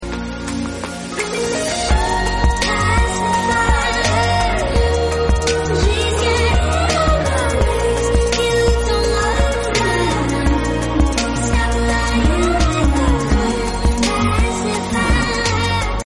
sped upp